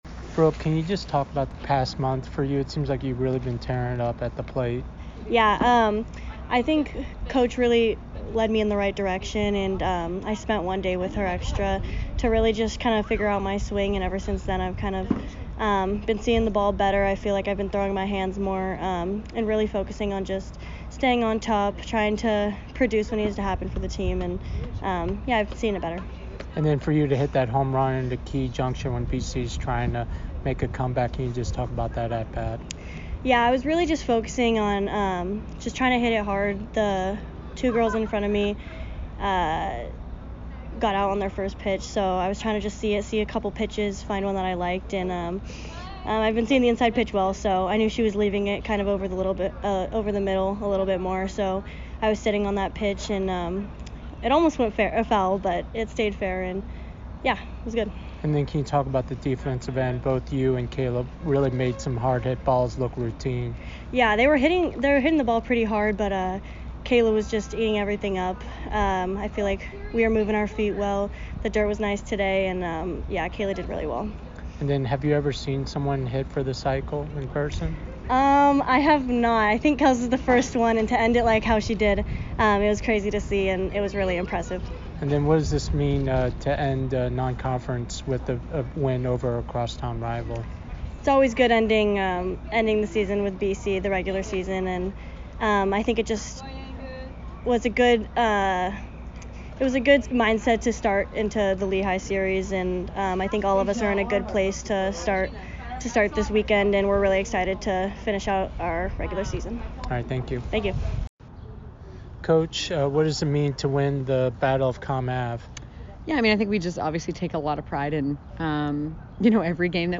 Softball / Boston College Postgame Interview